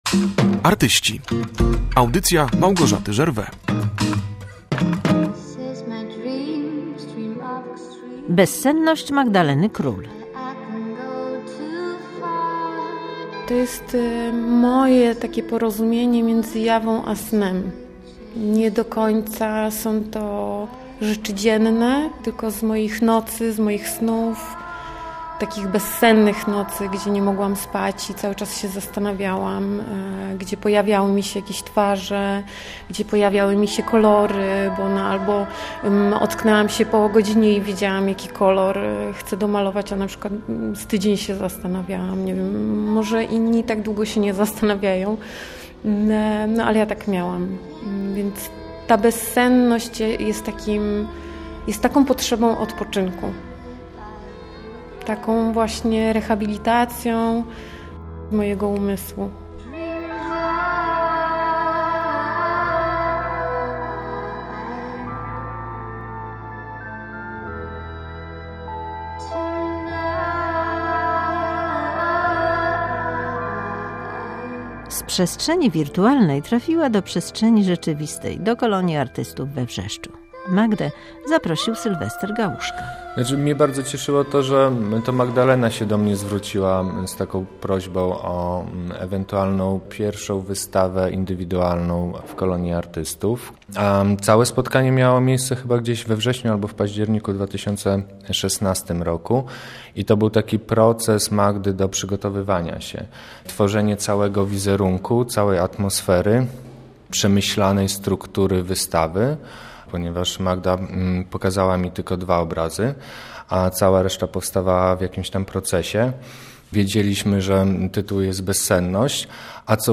audycje galeria reportaż sztuka wystawa